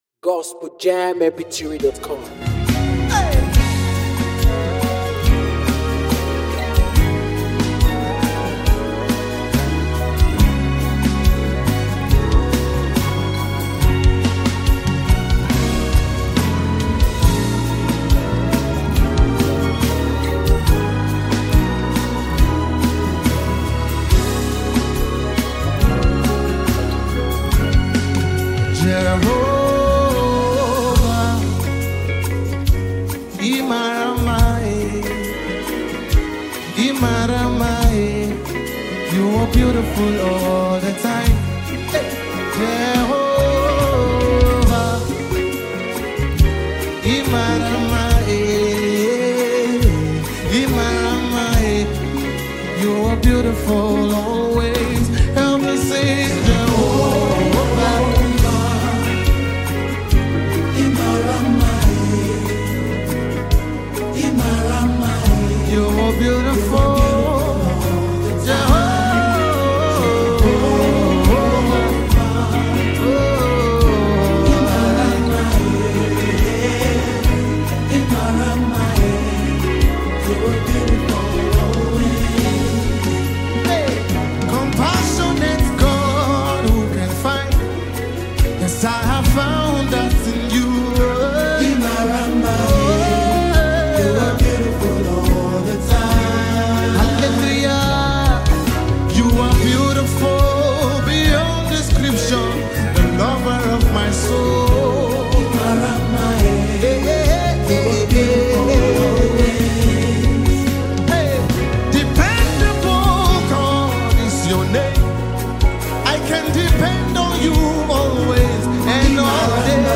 NUMBER 1 AFRICA GOSPEL PROMOTING MEDIA
The live session